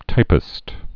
(tīpĭst)